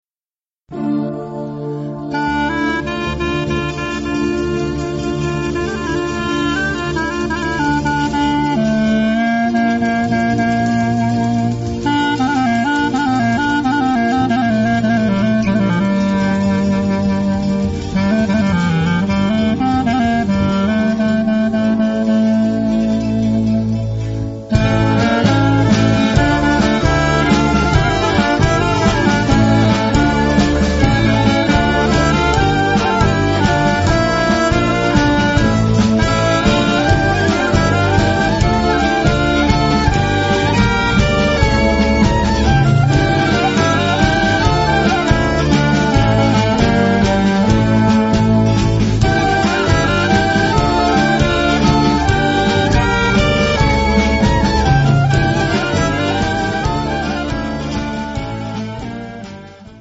Balkan Gypsy party music